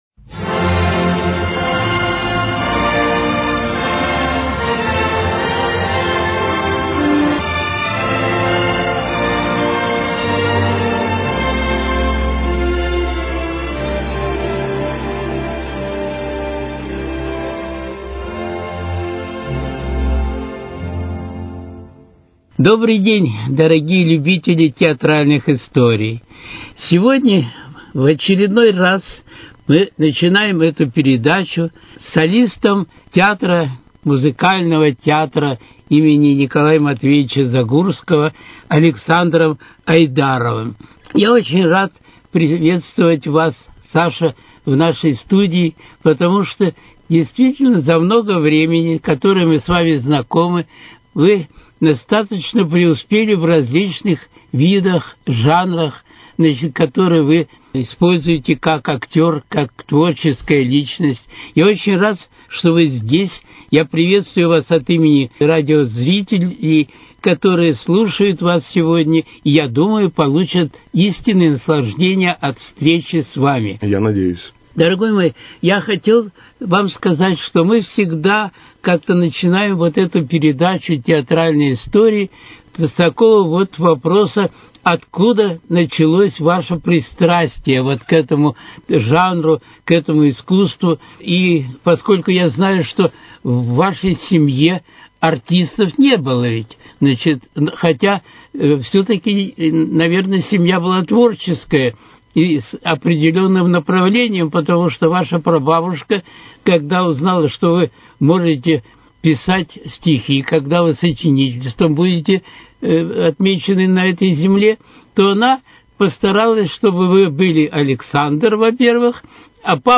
Театральные истории: Беседа